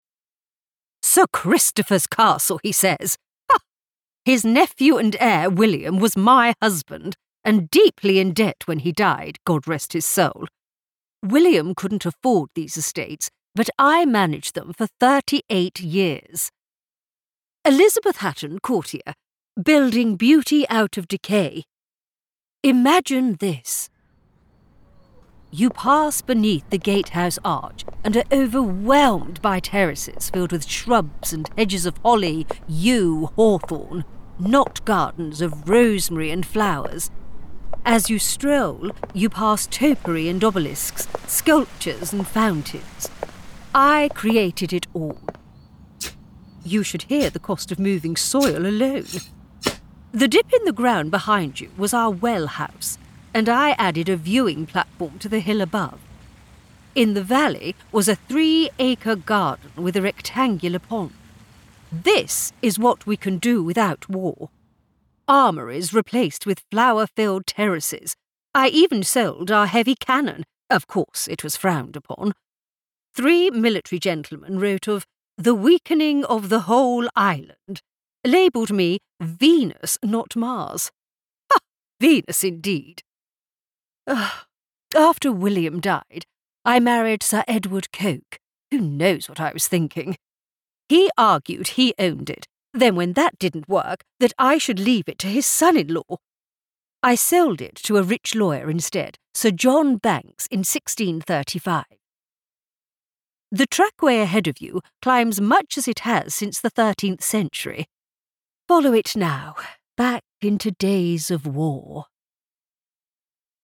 Step back in time and delve into the rich history of Corfe Castle with our immersive audio tour. Walk in the footsteps of eight historical figures, to understand their unique perspective of Corfe Castle.